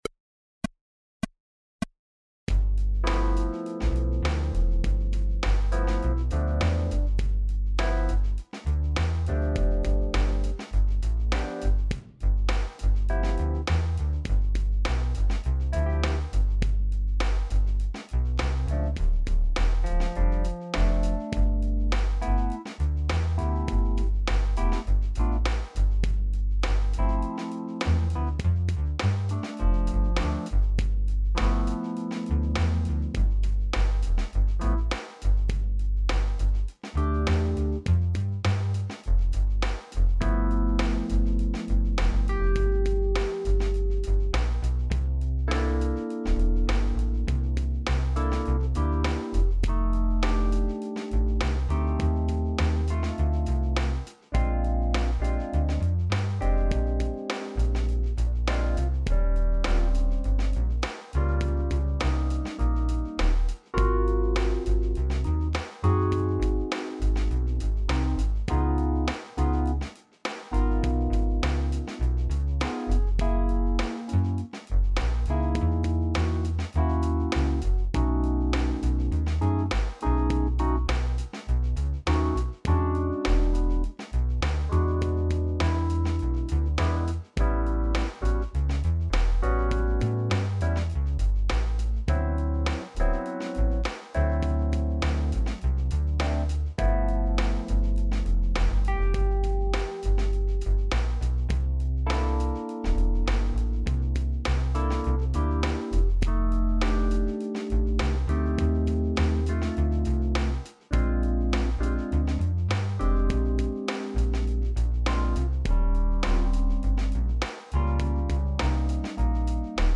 jazz riffs in all keys